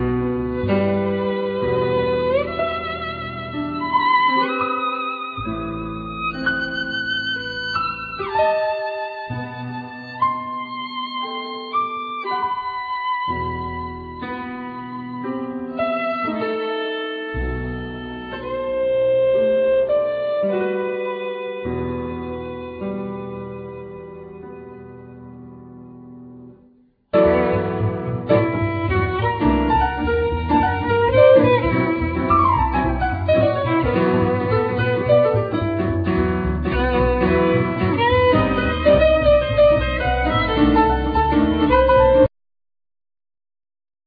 Piano
Violin
Bass